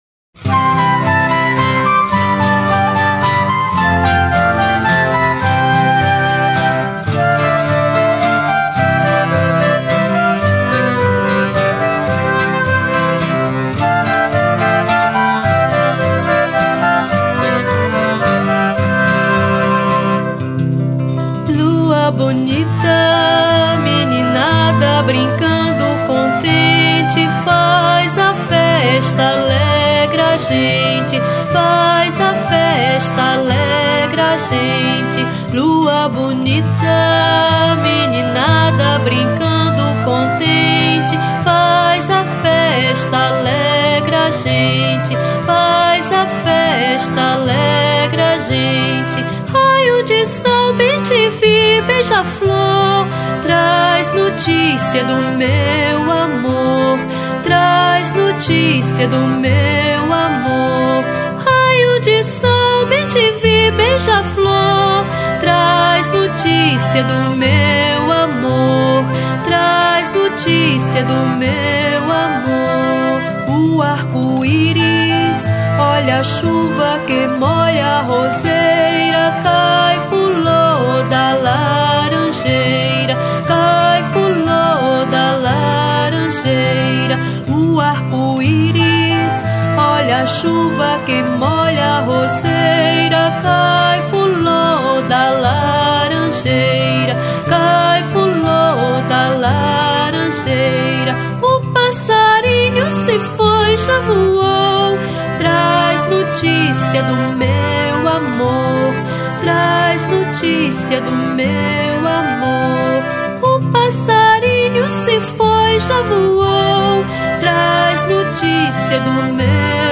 Voz
Violao Acústico 6
Flauta
Teclados